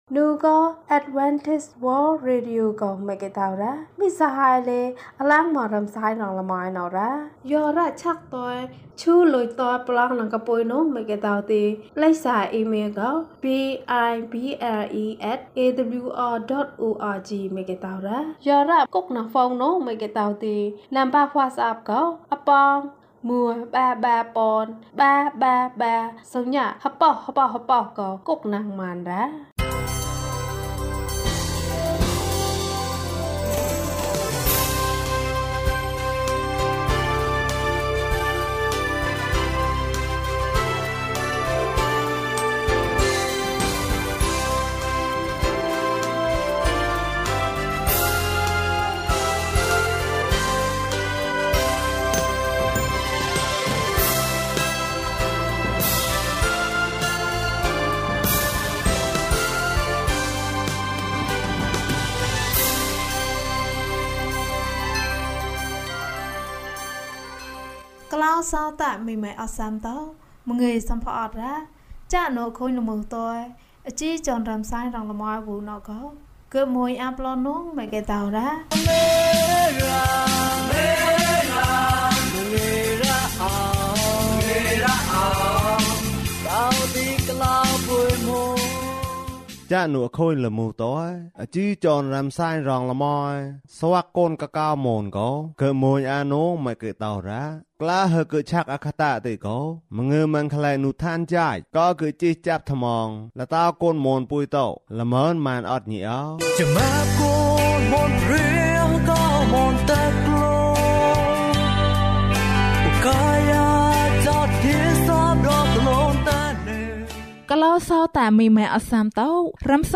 လောက၌အလင်း။ ကျန်းမာခြင်းအကြောင်းအရာ။ ဓမ္မသီချင်း။ တရားဒေသနာ။